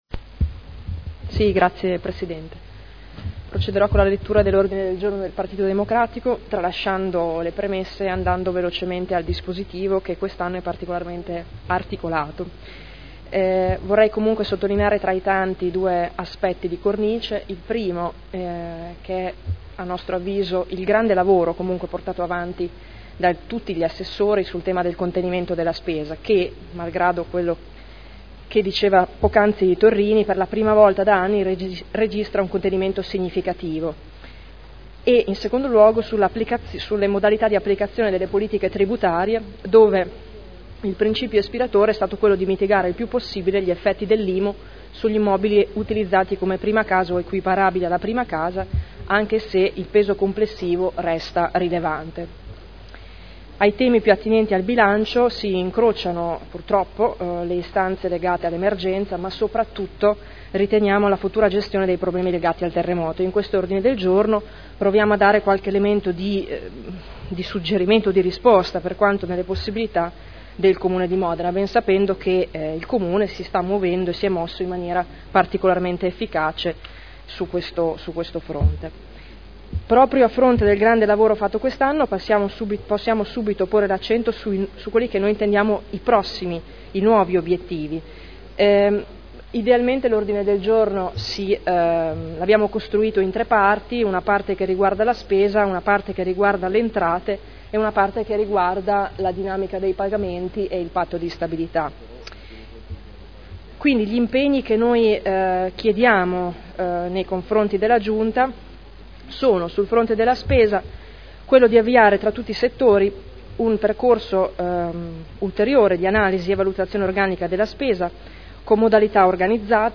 Seduta dell'11 giugno Presentazione di odg da parte del gruppo pd